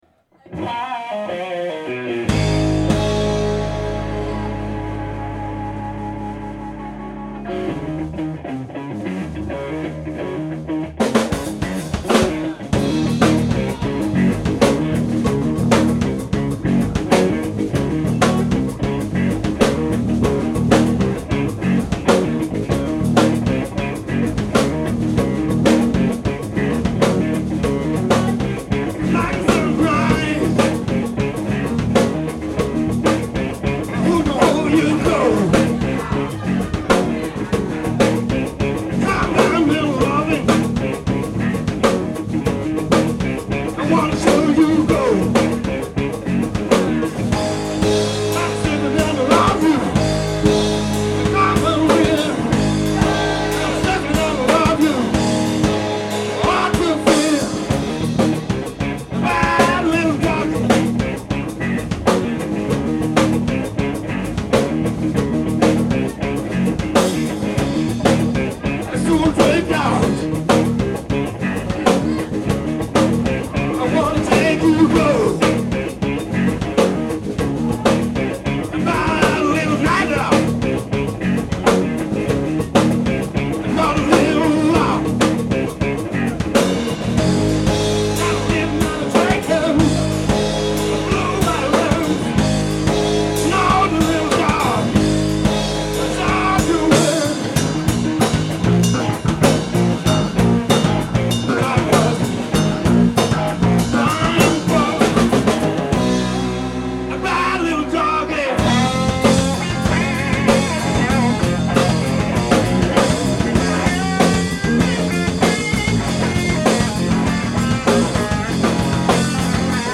LIVE EN PUBLIC (1983 - 2020) - Un résumé...
L'Amstel Pub (Cannes) 9 Nov 2002
(1er morceau de notre 1ere date)